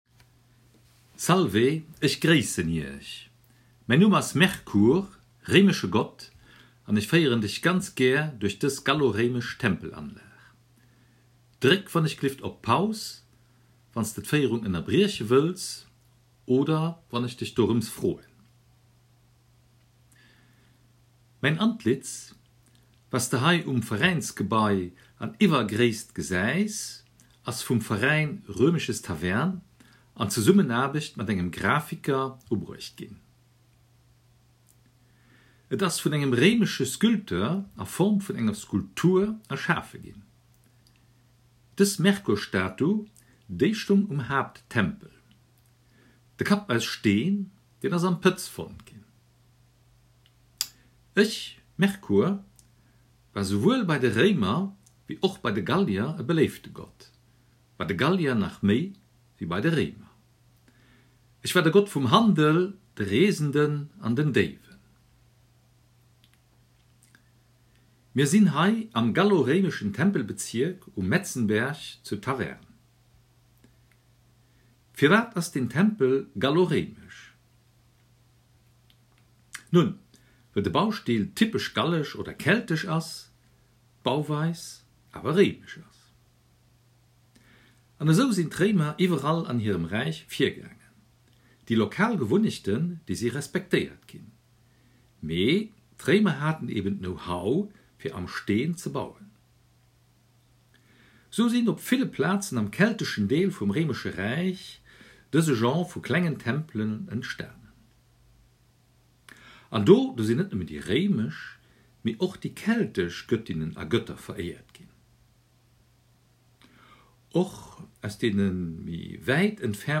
Besuchen Sie ganz einfach die nachfolgende Seite: Audioführung durch die Tempelanlage